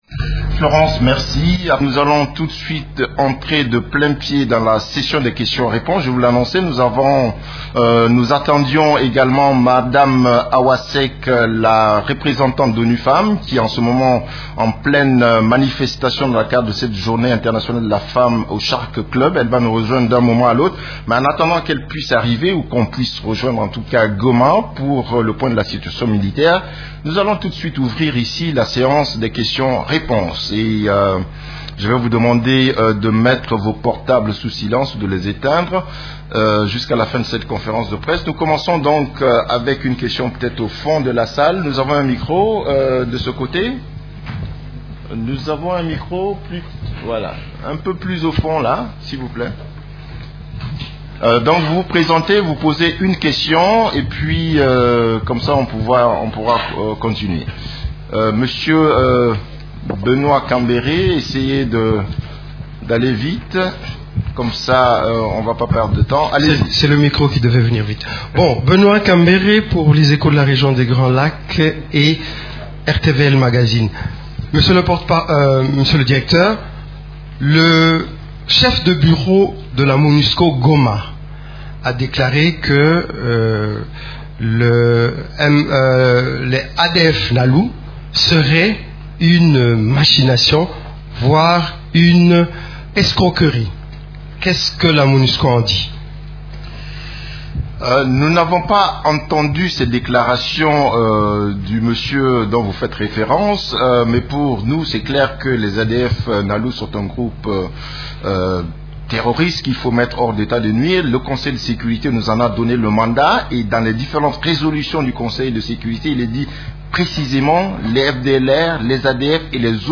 Conférence de presse du 8 mars 2017
La conférence de presse hebdomadaire des Nations unies du mercredi 8 février à Kinshasa a porté sur la situation sur les activités des composantes de la MONUSCO, des activités de l’Equipe-pays ainsi que de la situation militaire à travers la RDC.